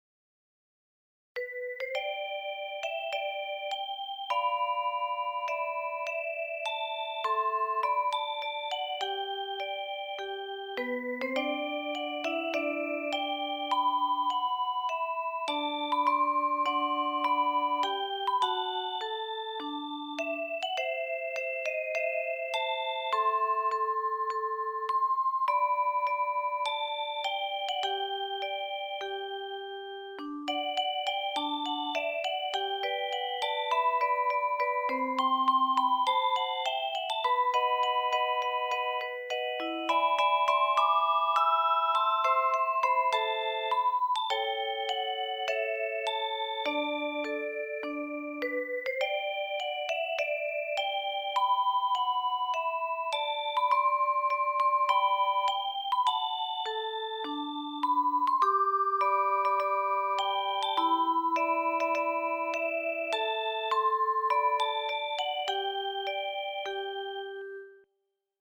■ 校歌（カラオケ・動画） kouka_karaoke.mp4 kouka_karaoke.mp4 ■ 校歌（メロディー） kouka.mp3 kouka.mp3